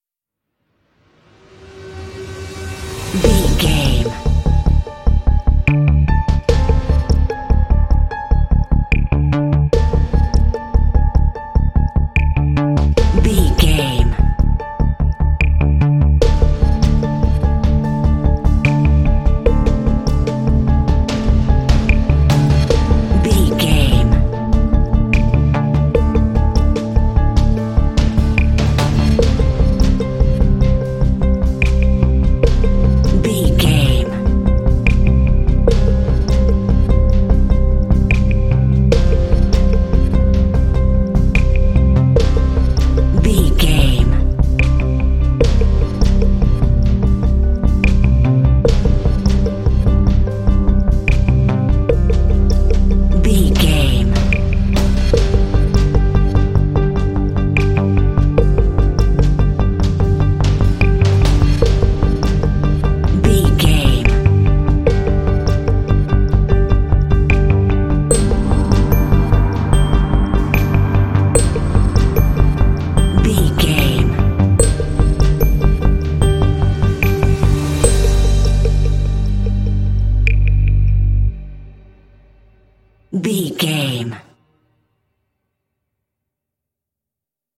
Aeolian/Minor
scary
suspense
foreboding
synthesiser
drums
strings
piano
cinematic
film score
contemporary underscore